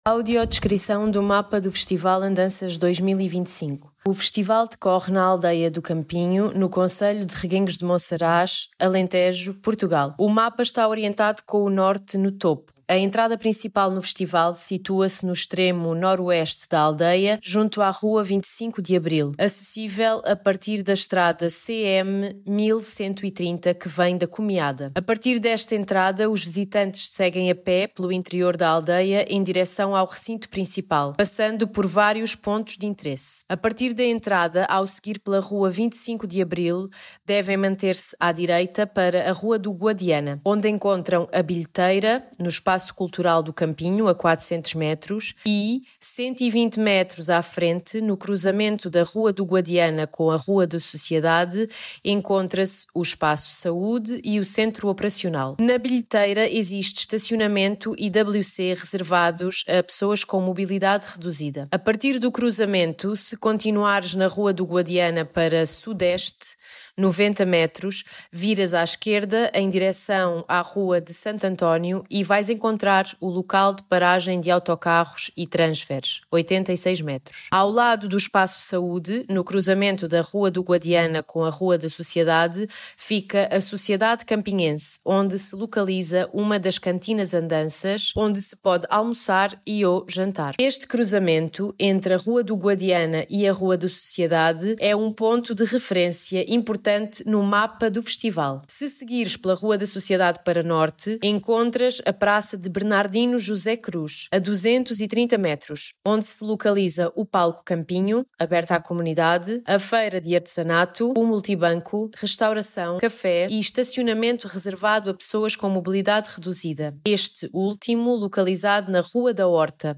audiodescricao-mapa-andancas-2025.mp3